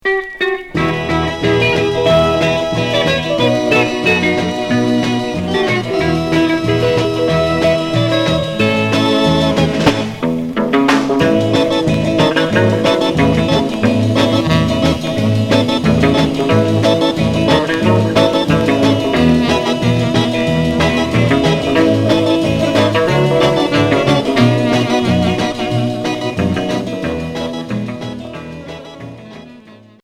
Rock instrumental Unique EP retour à l'accueil